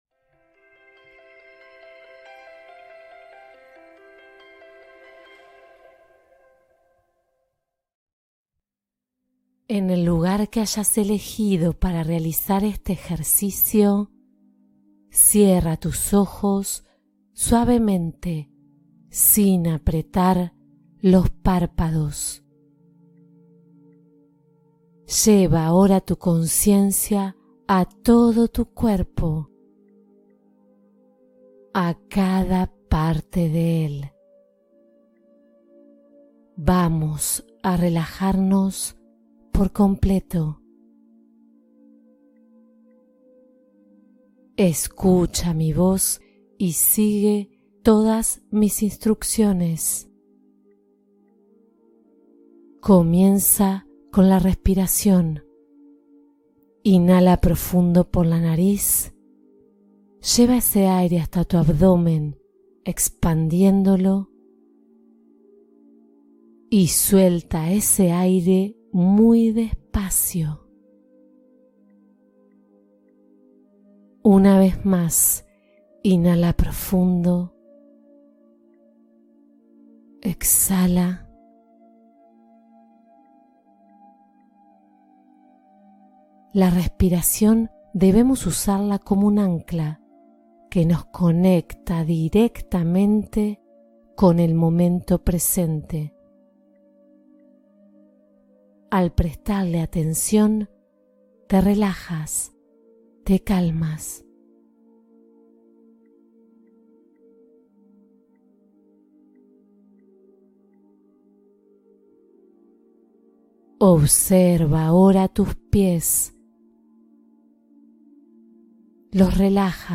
Limpieza energética profunda con mindfulness: meditación para sanar tu ser